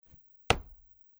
在很硬的地面上的脚步声－右声道－YS070525.mp3
通用动作/01人物/01移动状态/01硬地面/在很硬的地面上的脚步声－右声道－YS070525.mp3